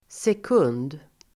Uttal: [sek'un:d]